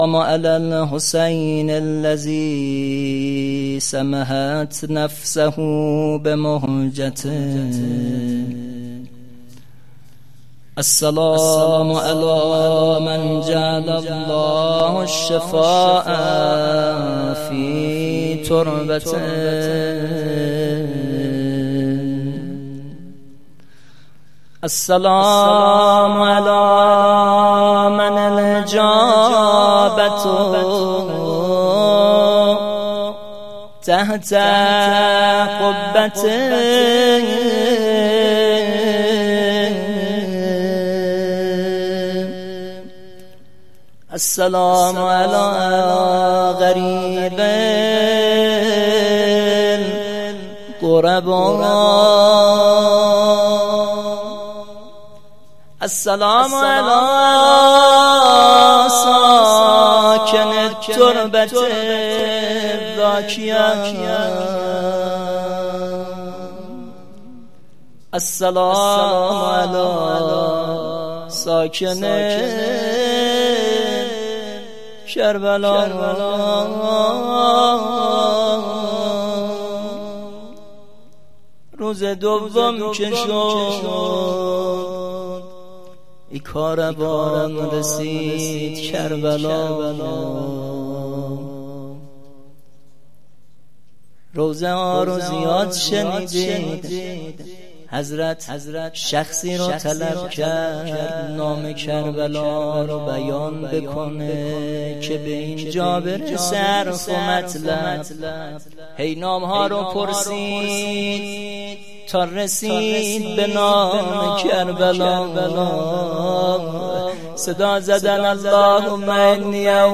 هیئت مهدیه احمد آباد